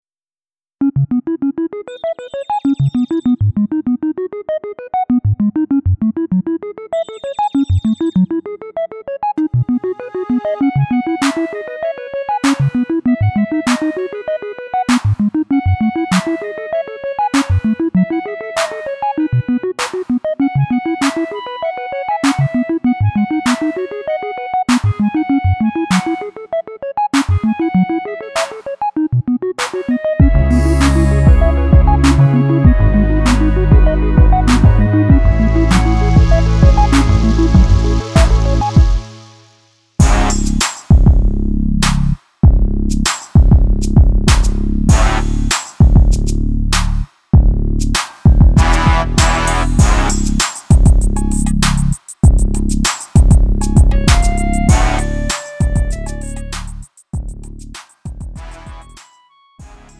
음정 원키 3:01
장르 가요 구분